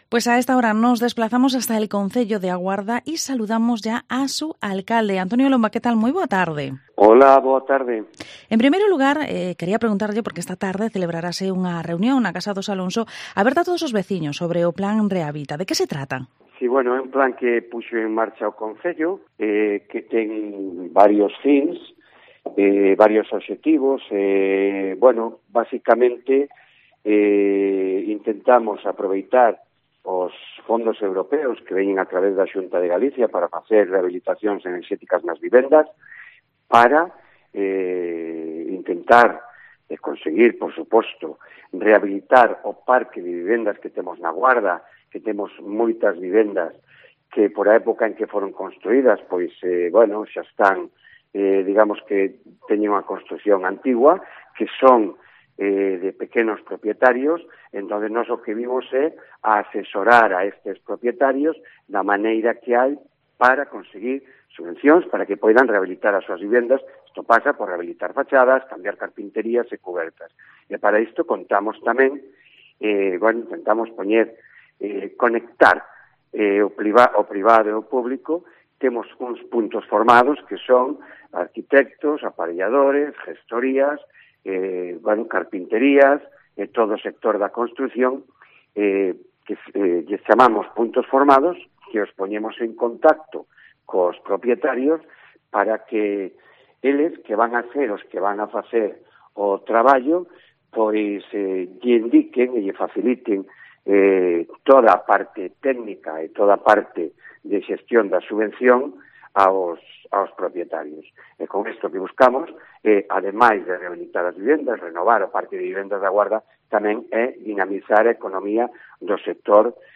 Entrevista a Antonio Lomba, Alcalde de A Guarda